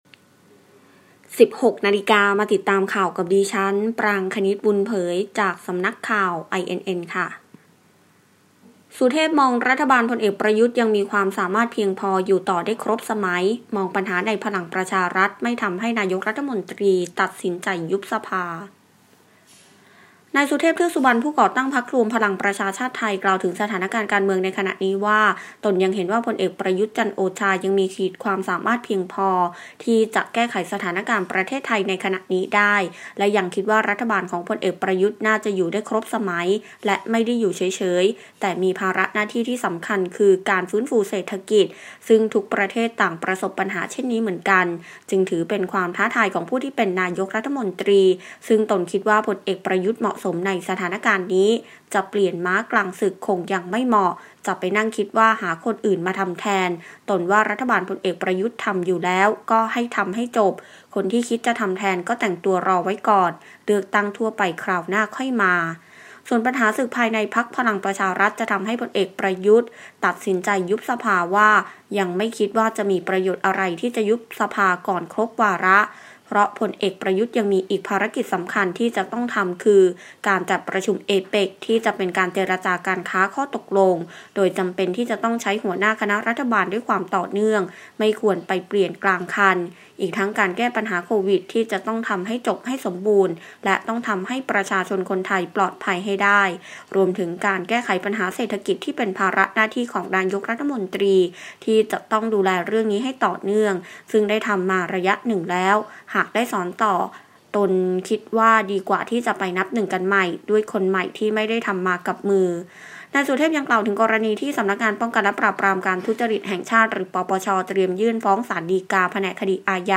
ข่าวต้นชั่วโมง 16.00 น.